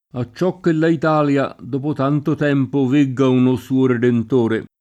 a©©0 kke lla it#lLa, d1po t#nto t$mpo, v%gga uno S2o redent1re] (Machiavelli)